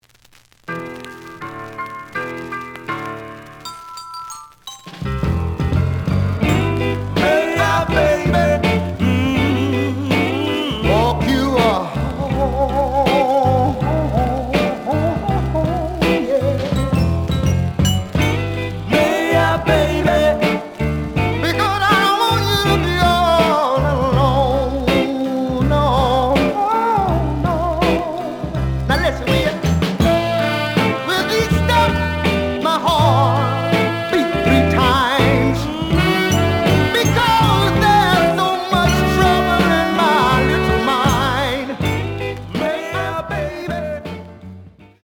The audio sample is recorded from the actual item.
●Genre: Soul, 60's Soul
Slight noise on both sides.